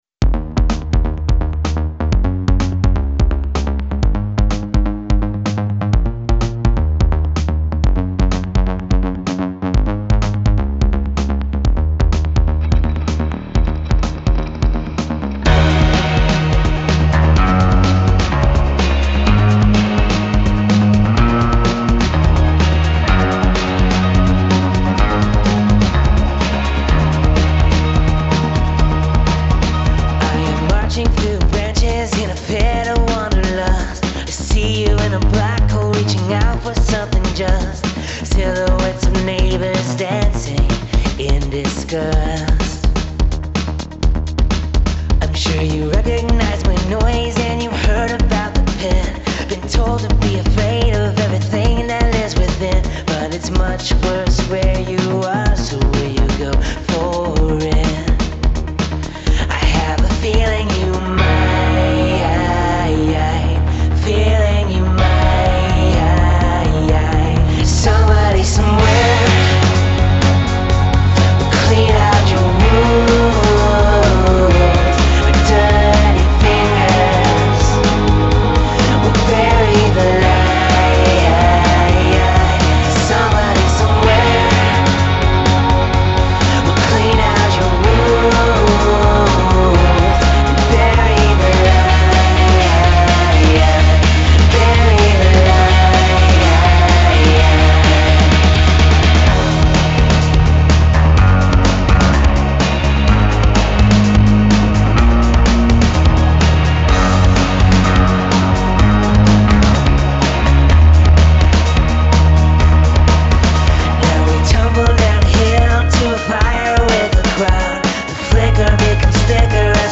Electo sounding beats off the top?